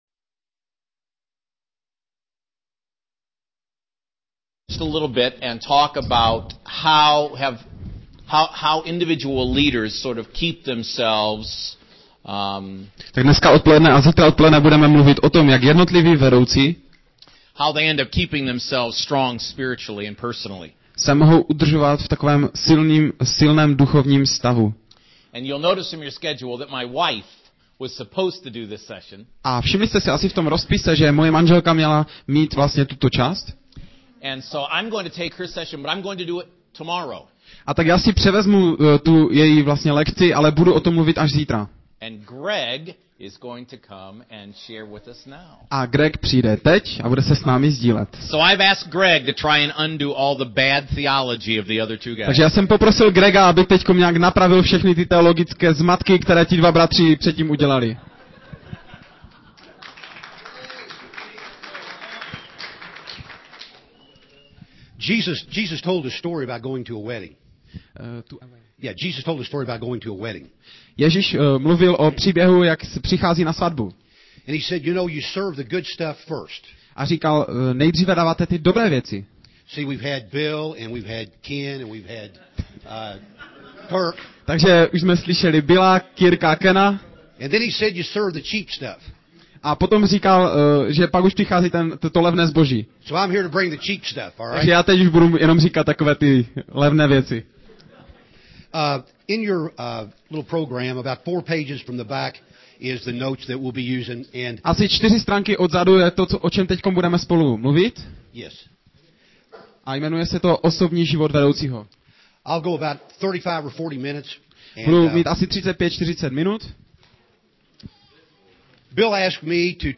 Konference NLI (říjen 2009)